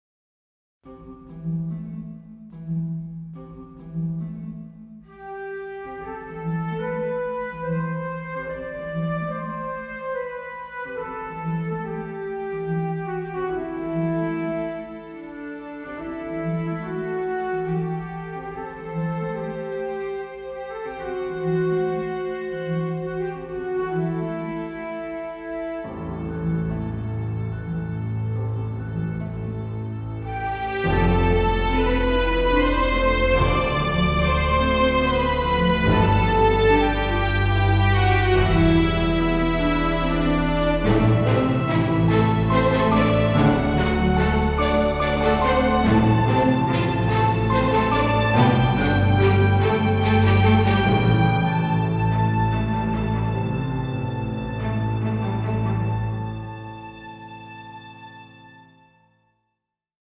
Mundane Love (orchestral
Music / Classical
orchestral theme instrumental sad melancholic romantic hopeful
flute